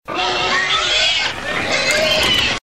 PIGS SQUEALING.mp3
A couple of pigs squealing in a small farm hen house.
pigs_squealing_vbk.ogg